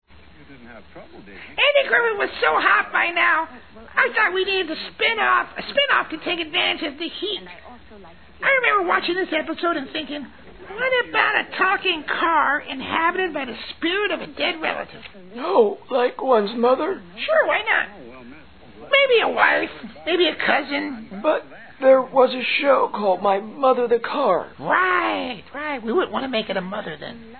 Commentary by the Executive Producer and Network Executive Dolby Stereo